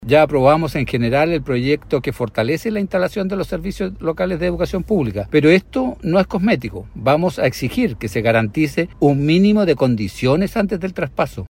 El senador UDI, integrante de la comisión de Educación de la Cámara Alta, Gustavo Sanhueza, afirmó que desde el Congreso están trabajando en una serie de proyectos, que buscan fortalecer la institucionalidad.